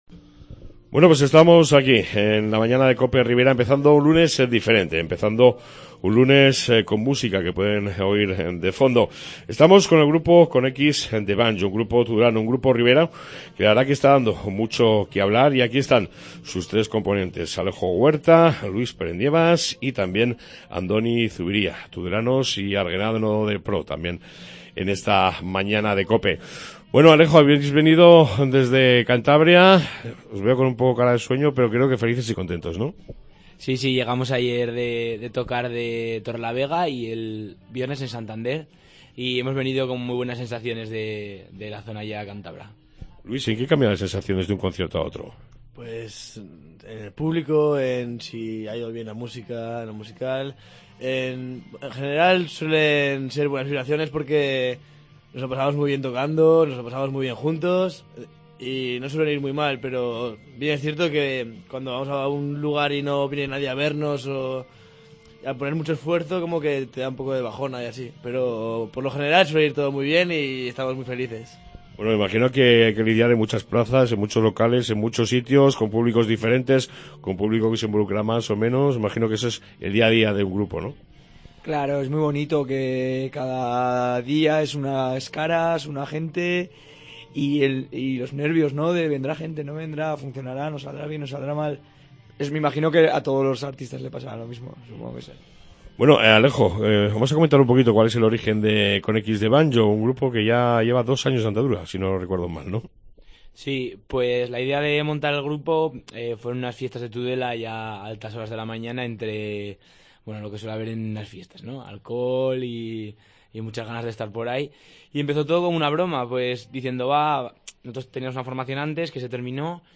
AUDIO: Hoy han venido a nuestros estudios para hablar de su larga gira, de como podemos ayudarlos en la IX edición de los premios de música...